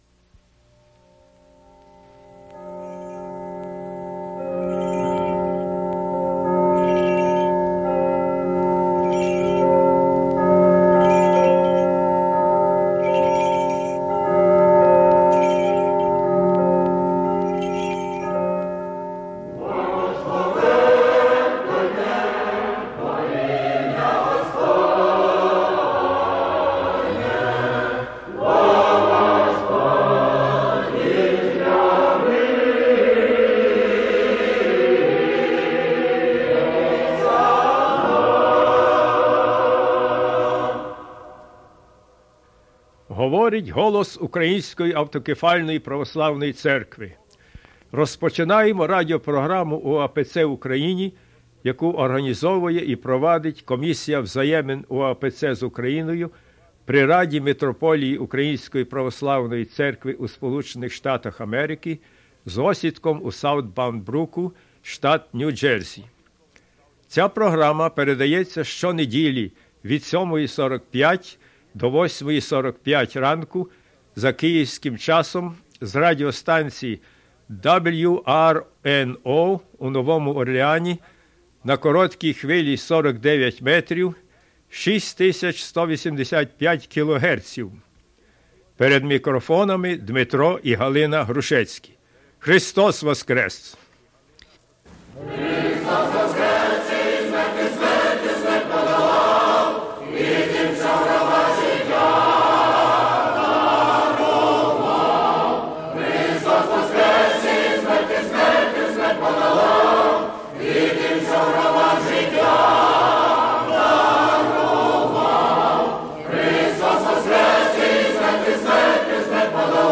Choral rendition of the Nicean Creed (excerpt)